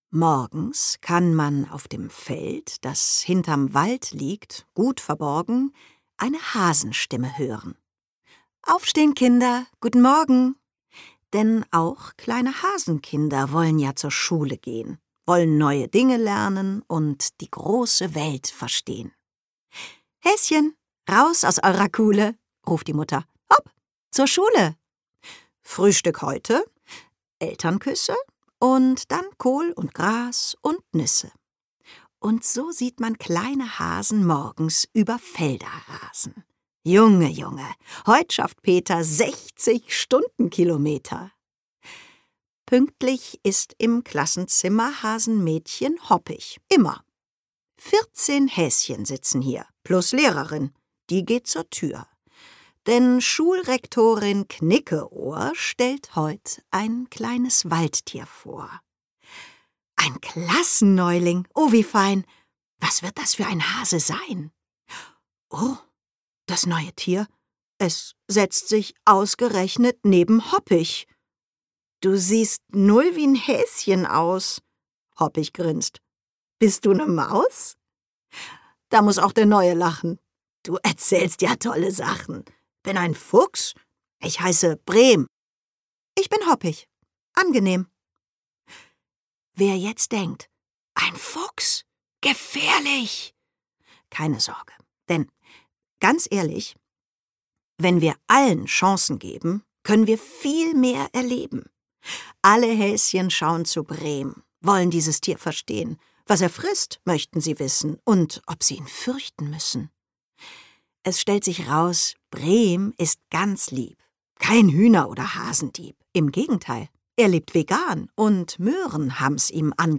Annette Frier liest
Sprecherin: Annette Frier
MP3 Hörbuch € 12,00 [D]